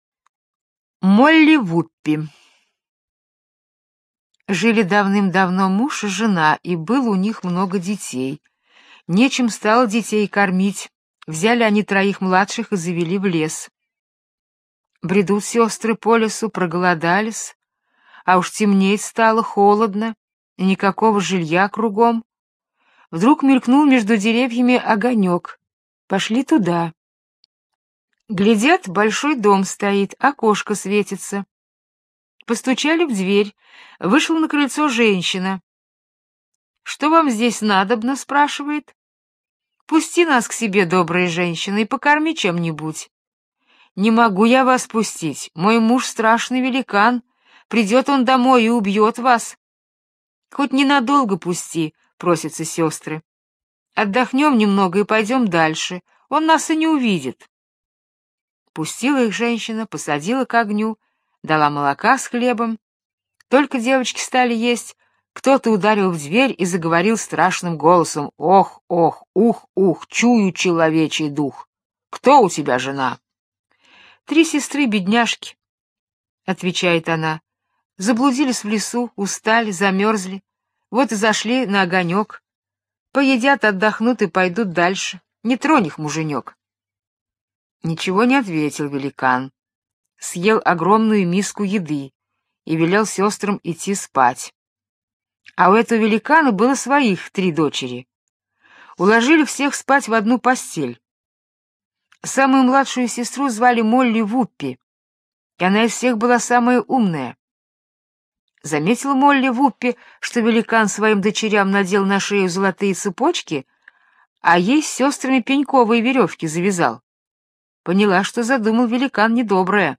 Молли Ваппи - британская аудиосказка - слушать онлайн